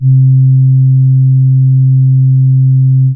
C#_07_Sub_03_SP.wav